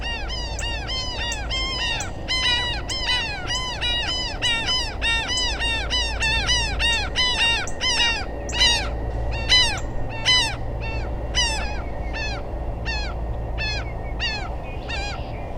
For fun and comic relief and because I featured this species in 2 recent posts, here’s a small flock of traveling Laughing Gulls.
laughing-gull.wav